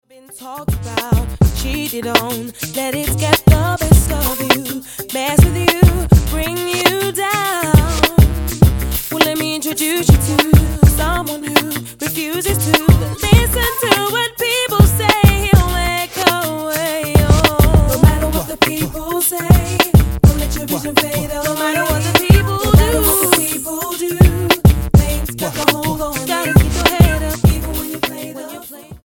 R&B
Style: Gospel